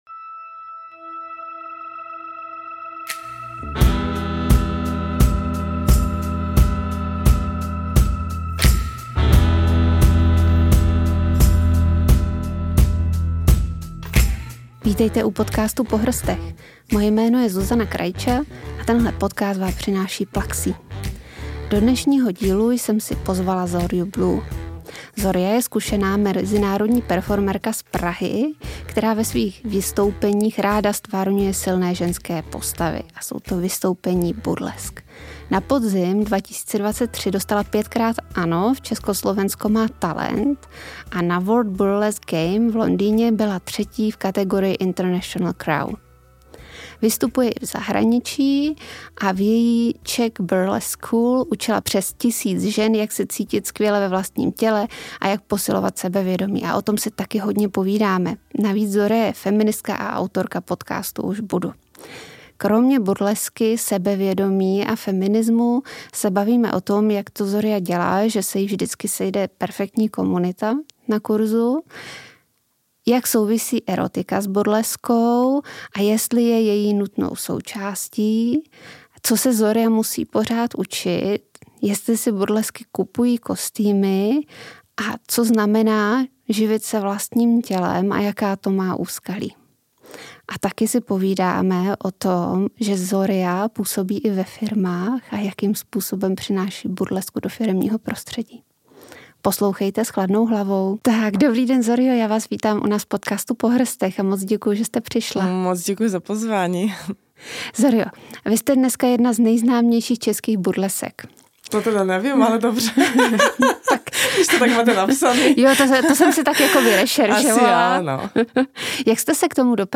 V rozhovoru si povídáme o tom, jak burleska souvisí s přijímáním vlastního těla, prací s energií a kolektivem podporujících žen.